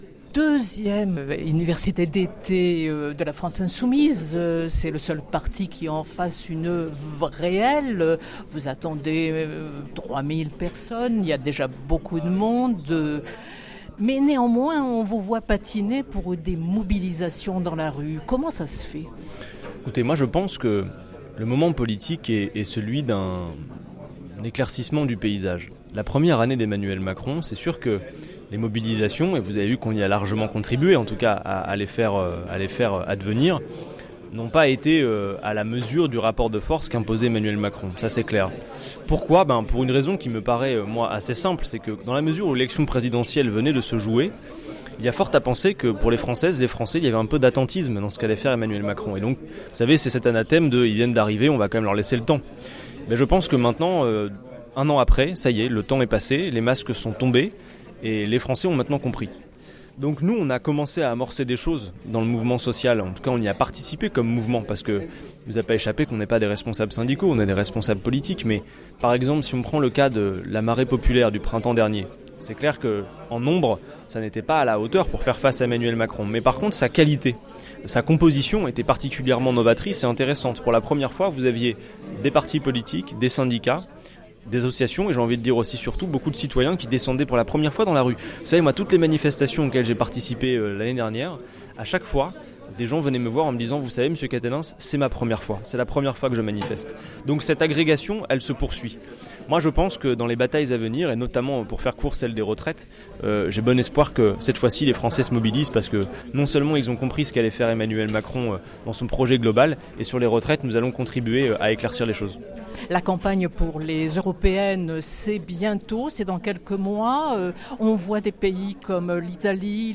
Les entretiens
Adrien Quatennens, député France Insoumise du Nord